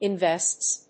発音記号
• / ˌɪˈnvɛsts(米国英語)
• / ˌɪˈnvests(英国英語)